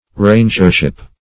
Rangership \Ran"ger*ship\, n. The office of the keeper of a forest or park.